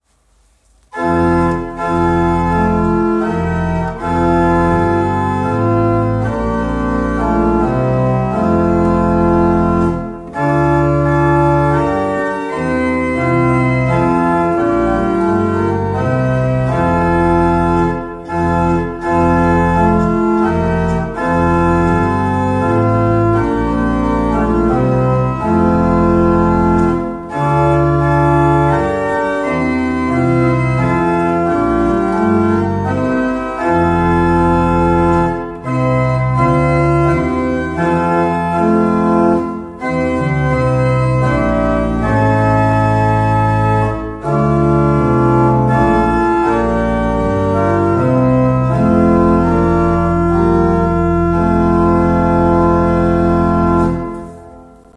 Orgel-„Wir-sagen-euch-an-1.m4a